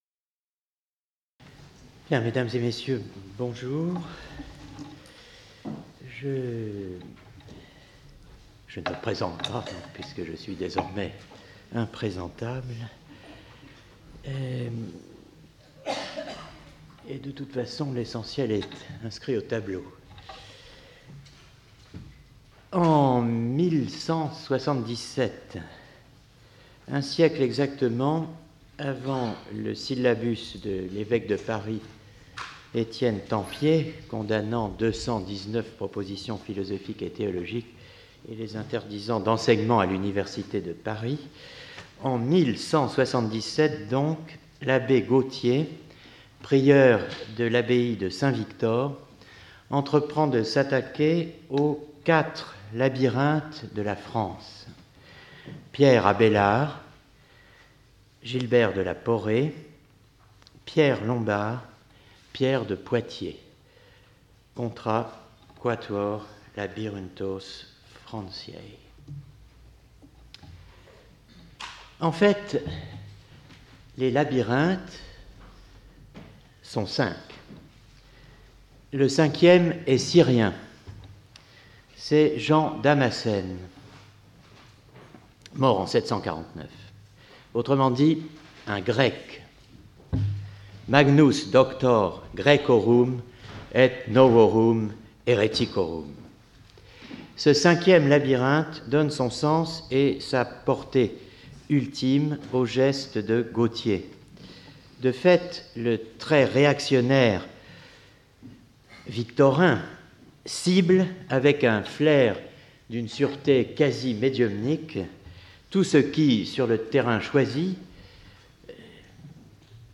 Colloque 30 Mai 2017 09:20 à 10:00 Alain de Libera Jean Damascène à Paris